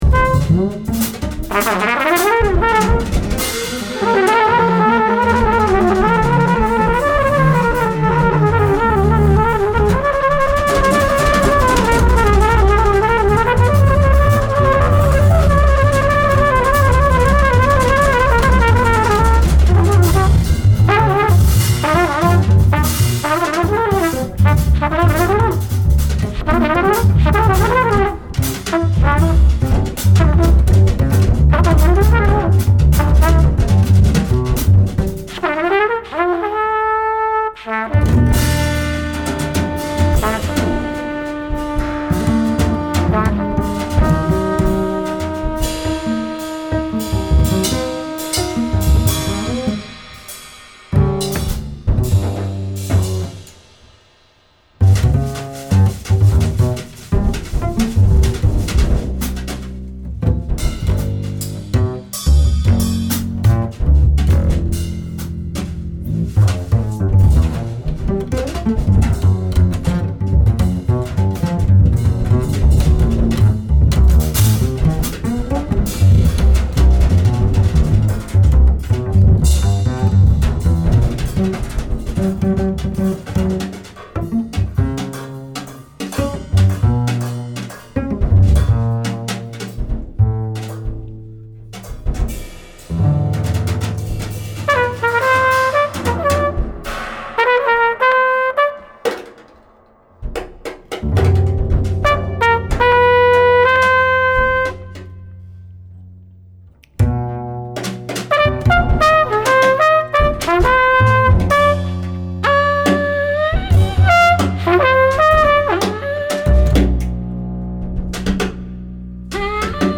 a percolating set
Trumpet
Bass
Drums